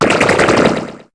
Cri de Tarenbulle dans Pokémon HOME.